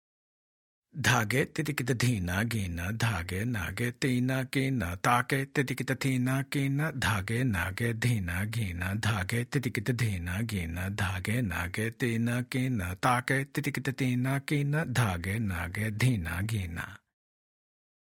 Demonstrations
1x Speed – Spoken